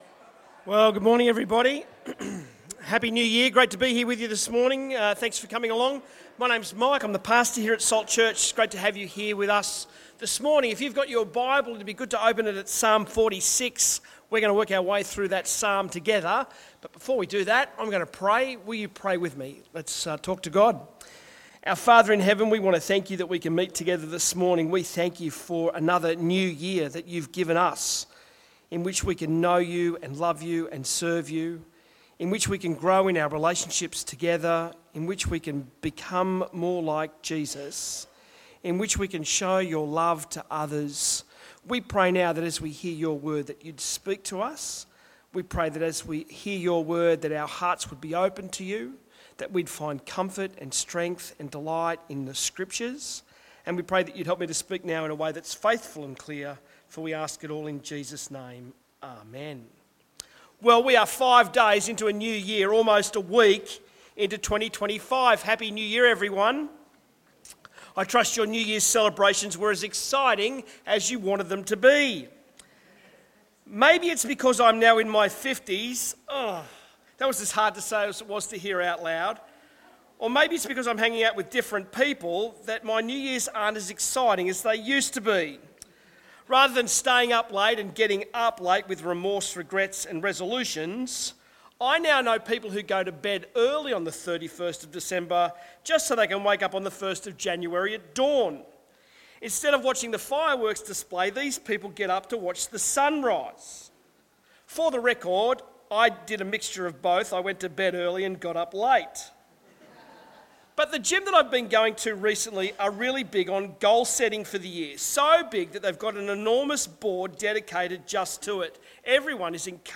Bible talk on Psalm 46 from the Summer Psalms 2025 series